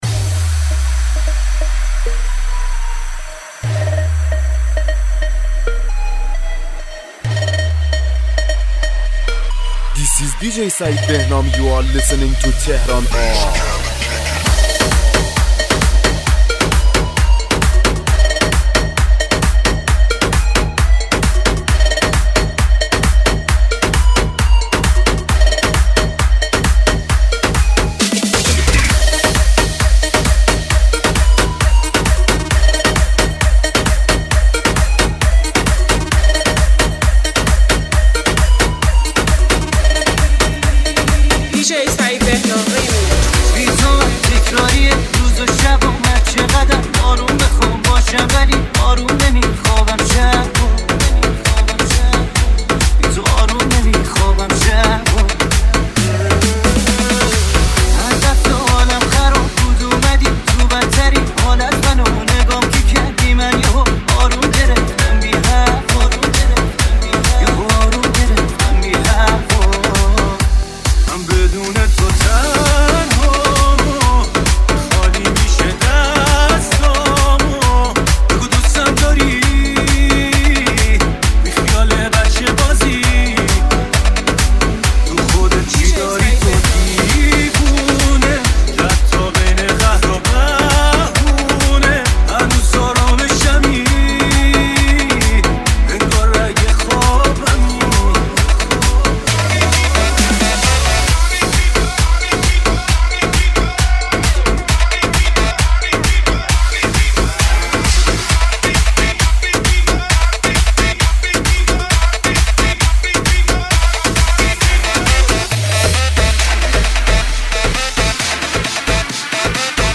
شاد پشت سرهم طولانی
میکس و زیبا منتخبی از موزیک های بترکون مخصوص مهمونی